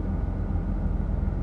idle.wav